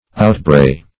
Search Result for " outbray" : The Collaborative International Dictionary of English v.0.48: Outbray \Out*bray"\, v. t. 1.